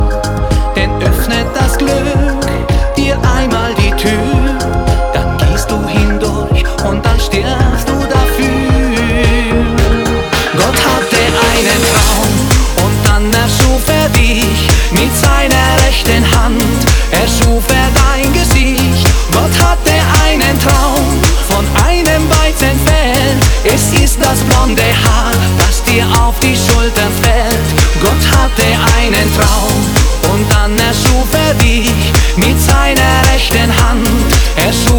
Жанр: Фолк-рок
# German Folk